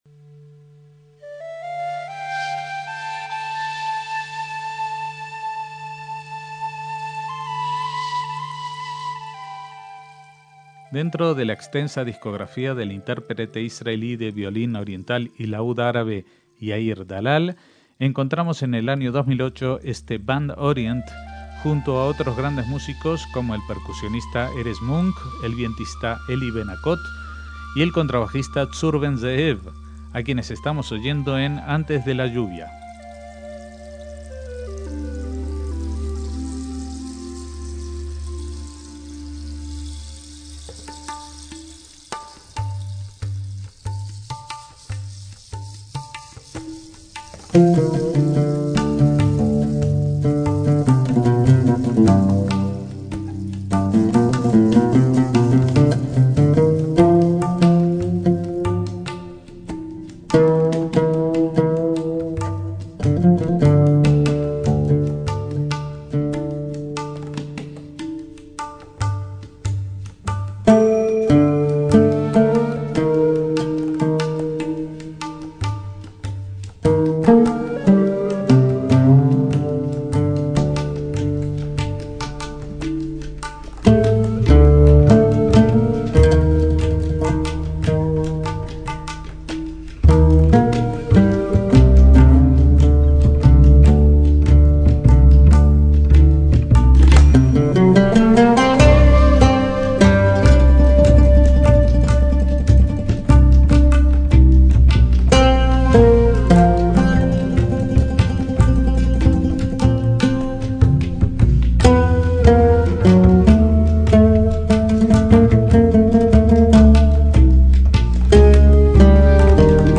un instrumento electrónico de viento
la tabla india y percusión tradicional de Oriente Medio
El resultado es una música llena de contrastes que mezcla los sonidos puros y antiguos del con la sonoridad más avanzada, el jazz y el estilo único de Dalal.